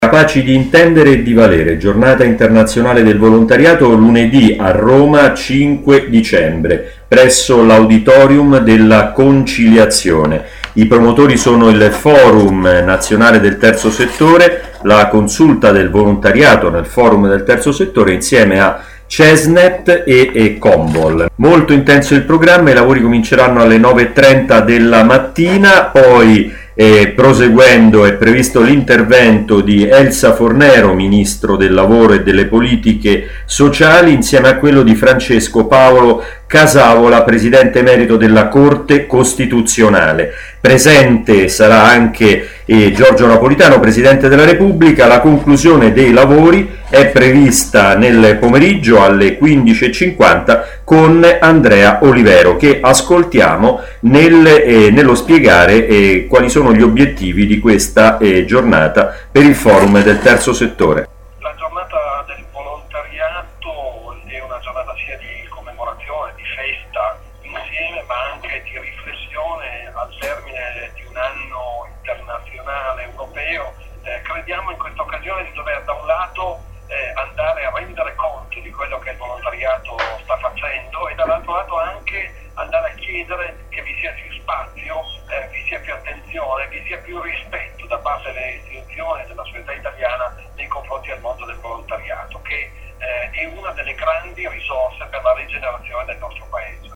Si riportano qui di seguito i file relativi alla Giornata Internazionale del Volontariato, svoltosi a Roma alla presenza del Presidente della Repubblica Giorgio Napolitano.
– il testo e l’audio dell’intervento di apertura di Andrea Olivero, Portavoce del Forum Nazionale Terzo Settore